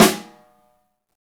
SNR XEXTS0DR.wav